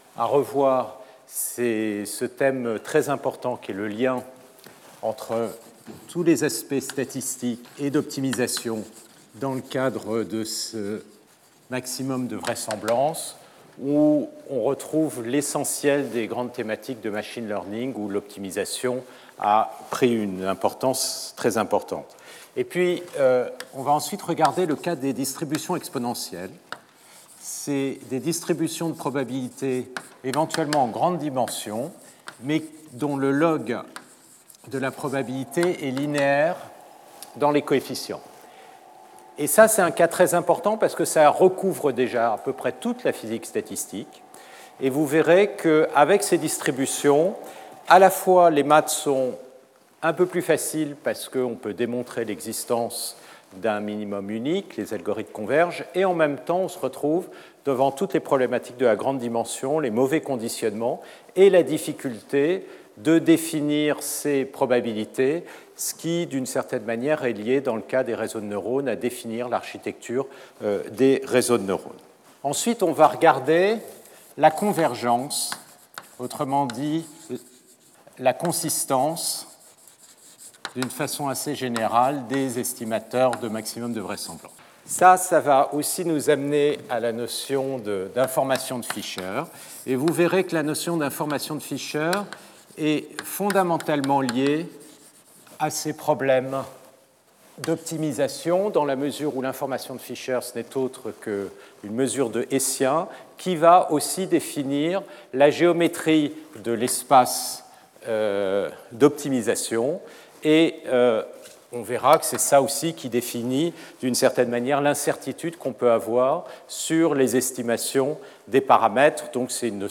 Lecture audio
Stéphane Mallat Professeur du Collège de France